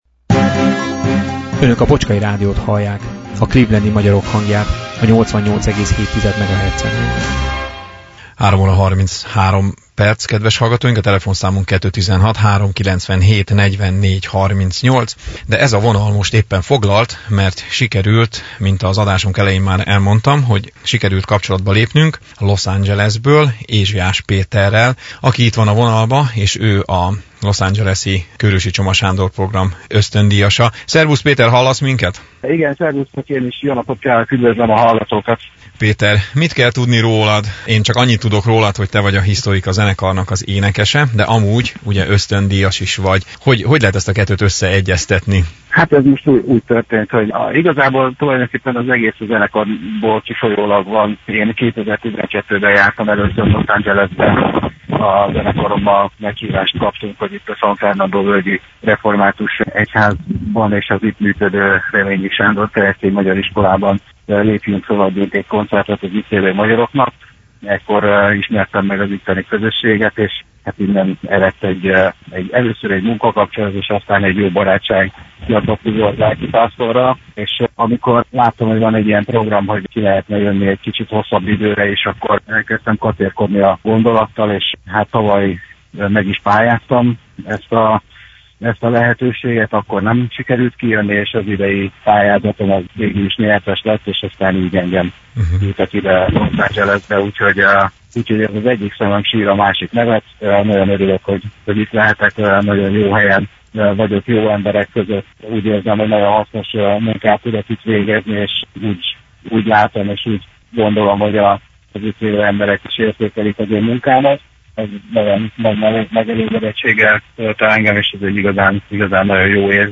Interjú – Bocskai Rádió